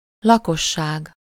Ääntäminen
Synonyymit habitant peuple groupe foule Ääntäminen France: IPA: [pɔ.py.la.sjɔ̃] Haettu sana löytyi näillä lähdekielillä: ranska Käännös Ääninäyte Substantiivit 1. lakosság Suku: f .